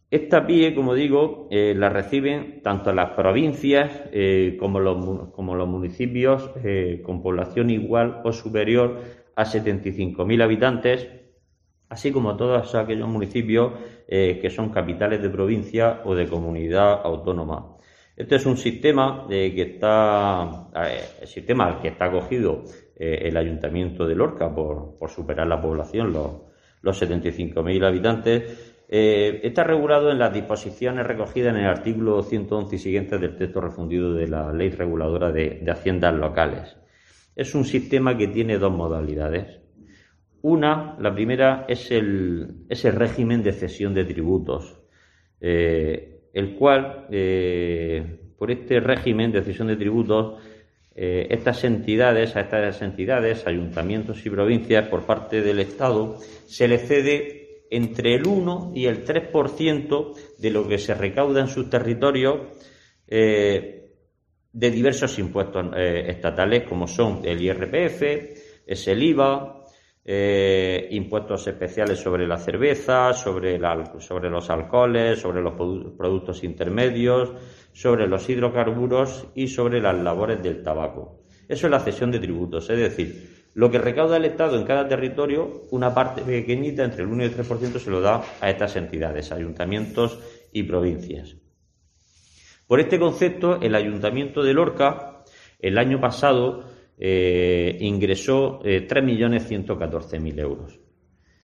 Isidro Abellán, concejal de Hacienda Ayto Lorca